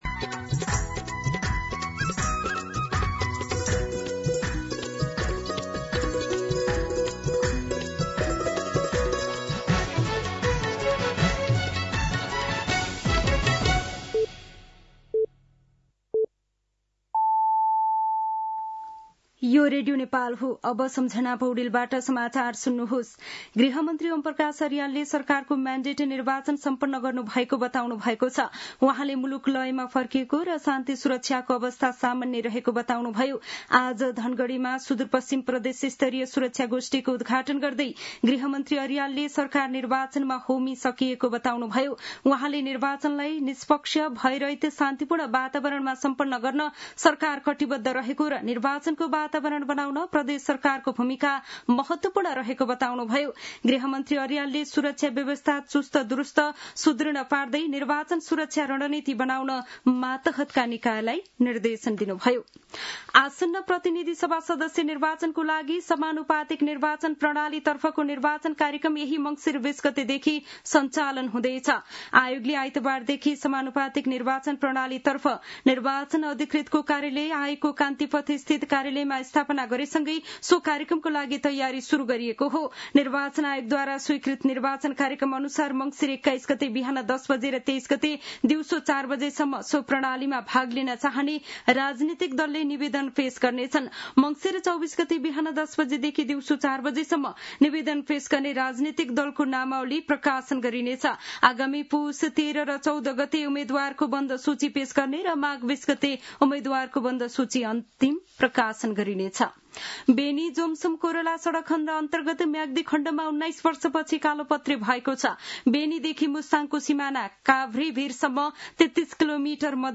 दिउँसो १ बजेको नेपाली समाचार : १६ मंसिर , २०८२